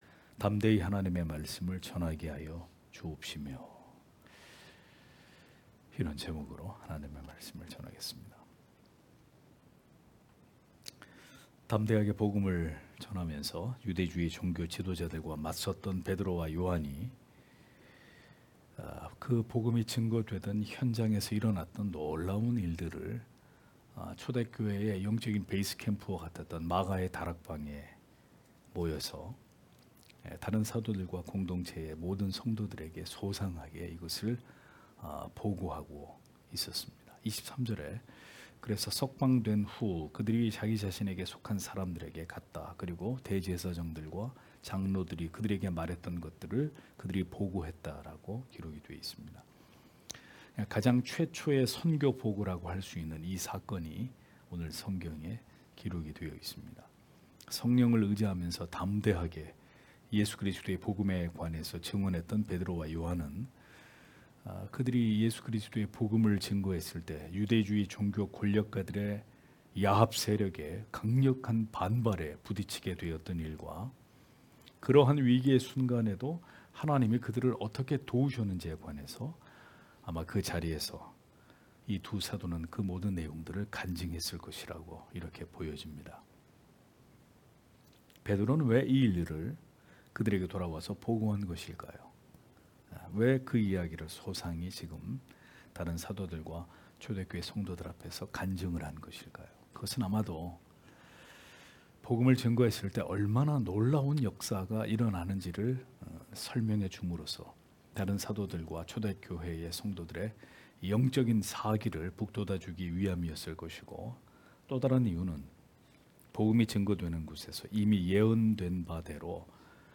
금요기도회 - [사도행전 강해 28]담대하게 하나님의 말씀을 전하게 하여 주옵시며(행 4장 32-31절)